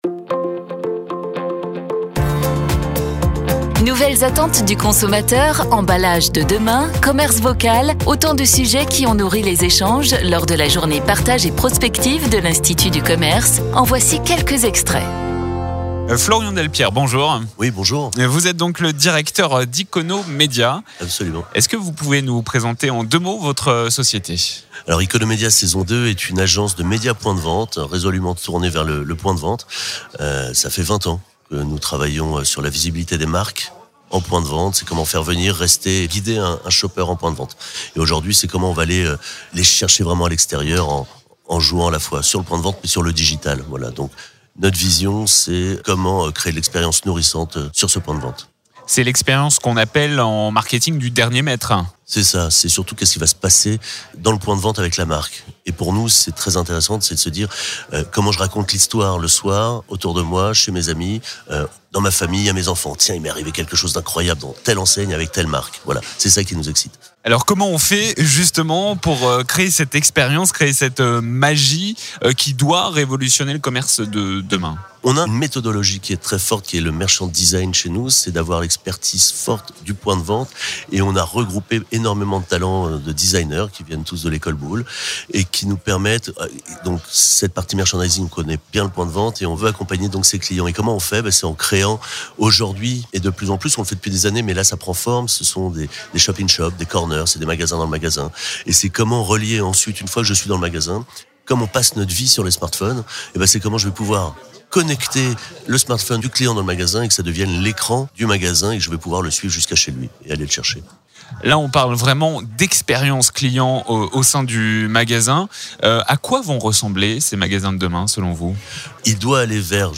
Les interviews Mediameeting de la Journée Partage et Prospective 2019